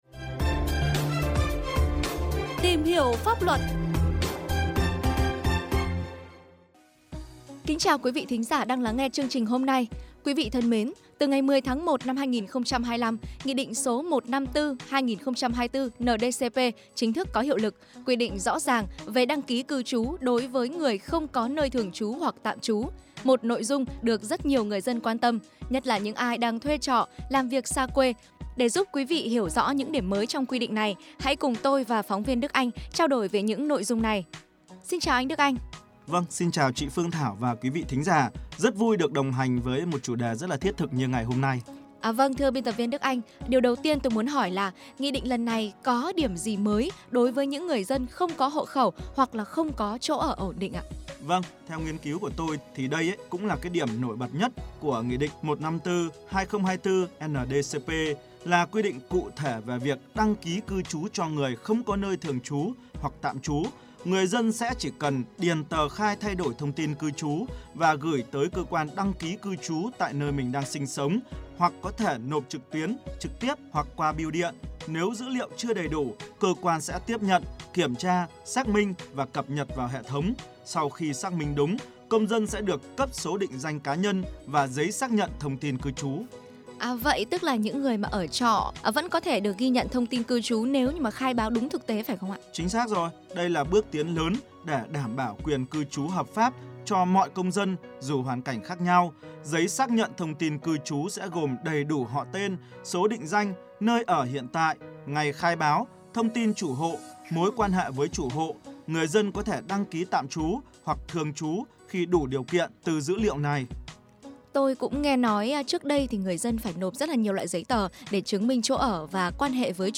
(Phóng Sự Phát Thanh) Quy định Mới Về đăng Ký Cư Trú Cho Người Không Có Nơi Thường Trú, Tạm Trú » Phổ Biến, Giáo Dục Pháp Luật Tỉnh Quảng Ninh